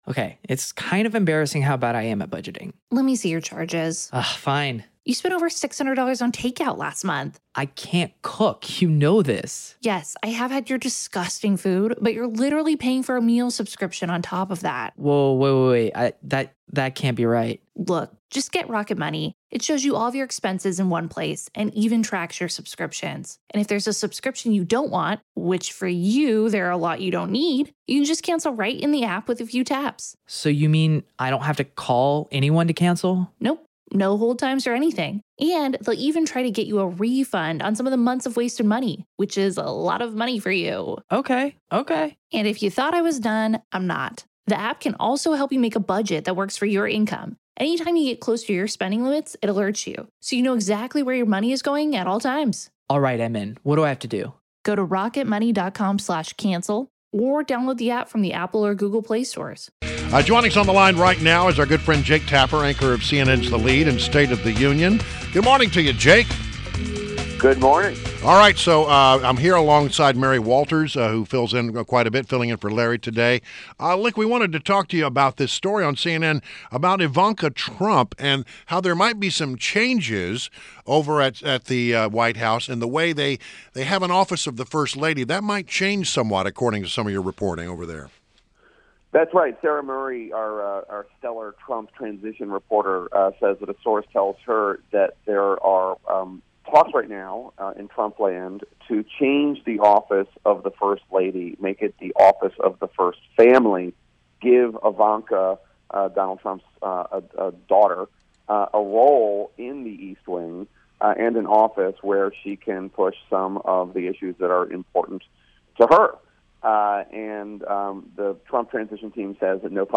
INTERVIEW — JAKE TAPPER – Anchor, CNN’s THE LEAD and STATE OF THE UNION